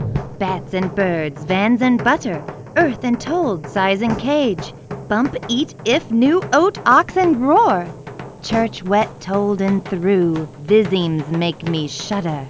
Recovered signal (M=4, Mu=0.005)
• There wasn't any perceptible difference between recovered signals with different Mu.